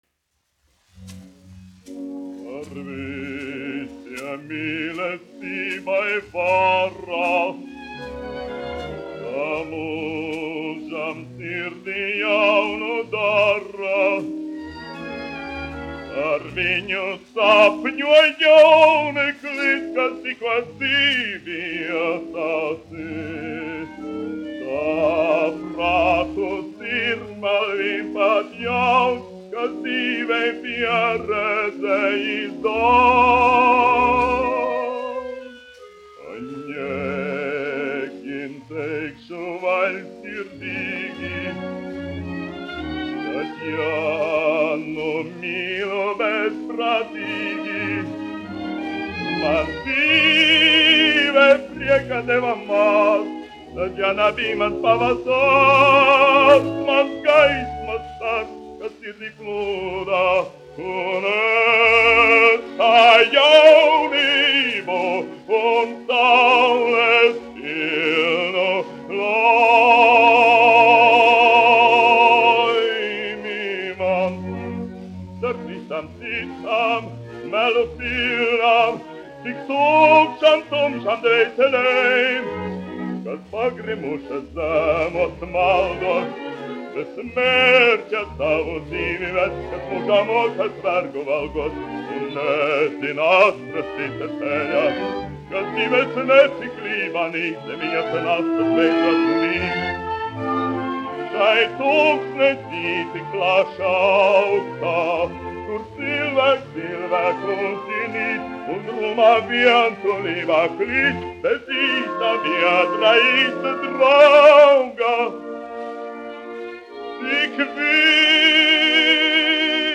Niedra, Jānis, 1887-1956, dziedātājs
1 skpl. : analogs, 78 apgr/min, mono ; 30 cm
Operas--Fragmenti
Skaņuplate
Latvijas vēsturiskie šellaka skaņuplašu ieraksti (Kolekcija)